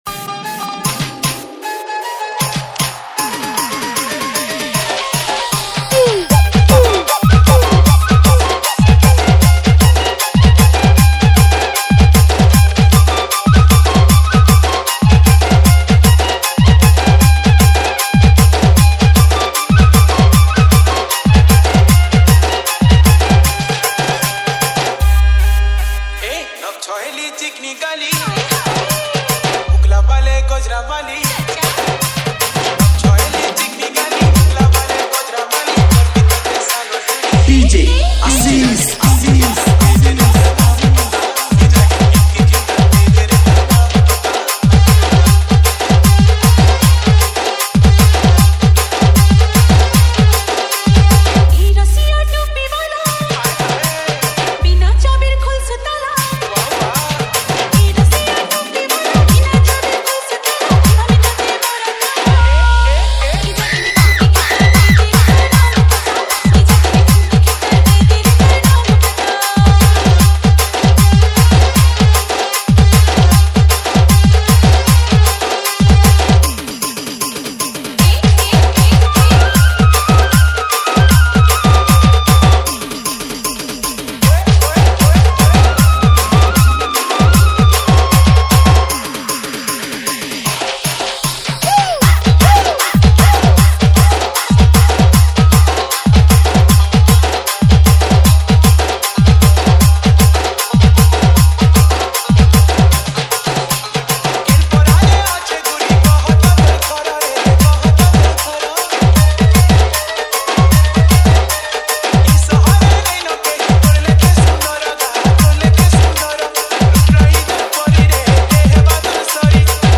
SAMBALPURI SAD DJ REMIX